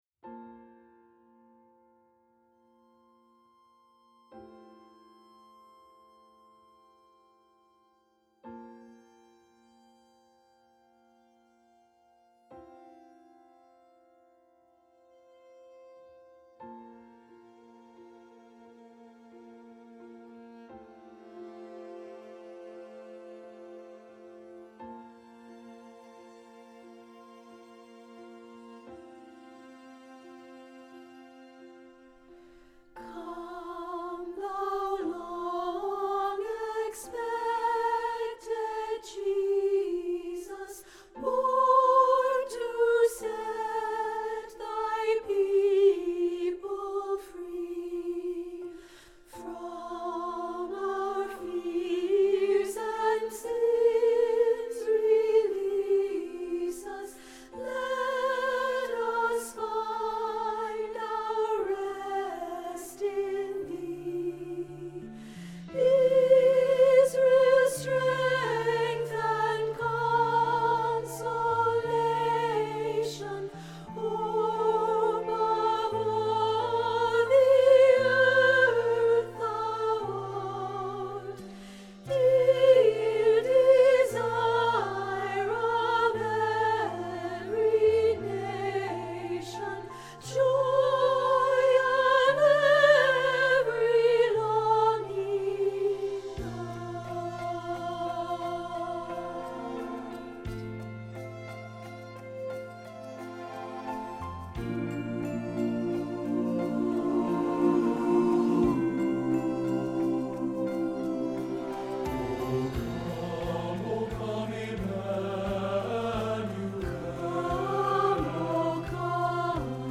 O Come - Soprano 2015-10-25 Choir